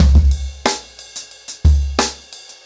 InDaHouse-90BPM.23.wav